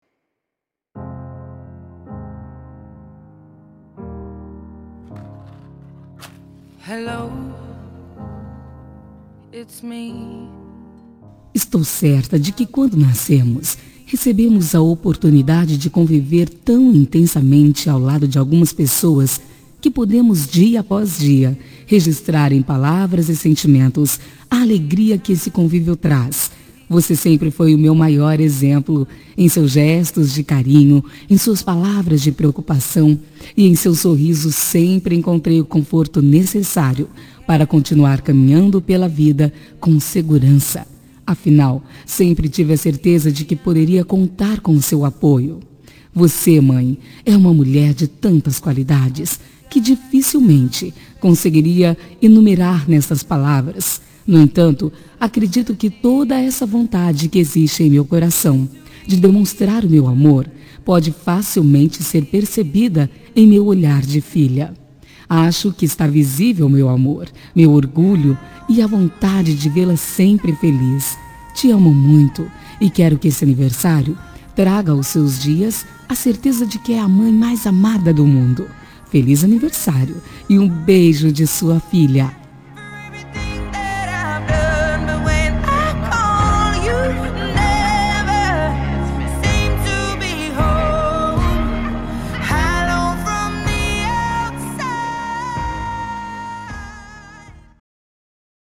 Telemensagem Aniversário de Mãe – Voz Feminina – Cód: 1396